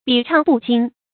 匕鬯不驚 注音： ㄅㄧˇ ㄔㄤˋ ㄅㄨˋ ㄐㄧㄥ 讀音讀法： 意思解釋： 匕：羹匙；鬯：古時用郁金草釀黑黍而制成的祭祀用的香酒。